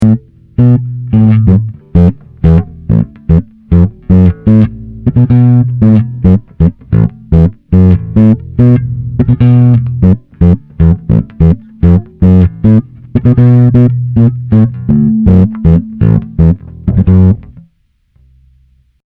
Tady jsem něco nabrnkl, ve dvojce to z monitorů leze celkem v pohodě, ale z nahrávky potom takhle podivně.
Však v té druhé nahrávce zvuk vynechává (dropout).